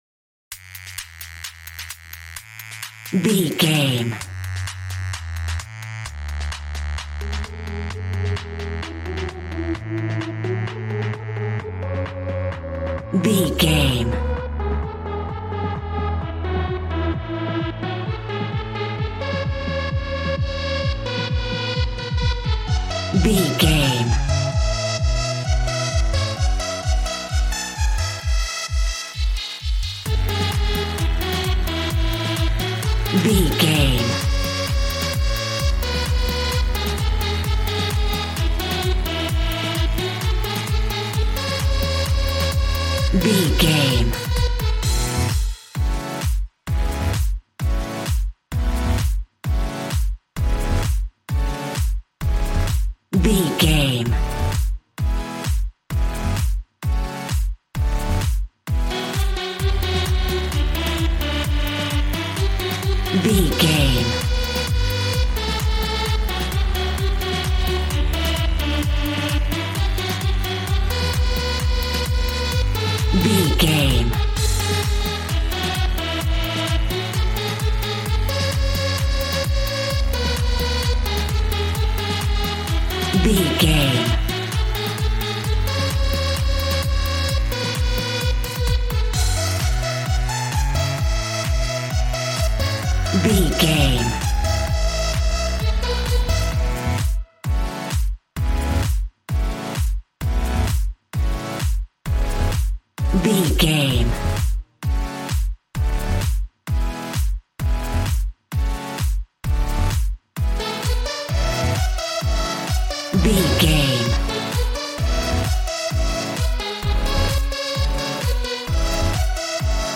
Ionian/Major
Fast
uplifting
lively
groovy
synthesiser
drums